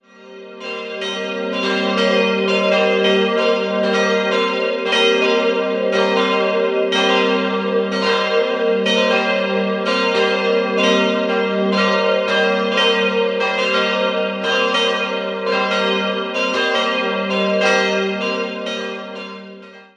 Jahrhundert errichtet und besitzt heute eine neugotische Ausstattung. 3-stimmiges TeDeum-Geläute: g'-b'-c'' Alle Glocken wurden 1953 von Friedrich Wilhelm Schilling in Heidelberg gegossen.